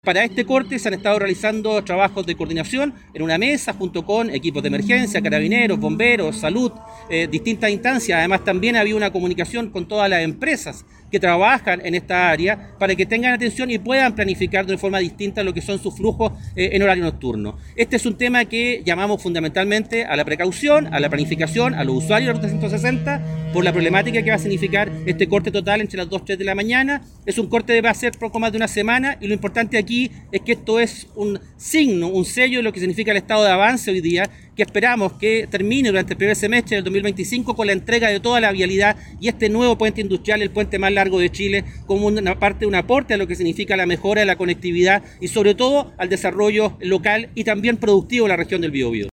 En tanto, el seremi de Obras Públicas, Hugo Cautivo, explicó que estos trabajos “son un signo del estado de avance de la obra, que esperamos entregar en el primer semestre de 2025”.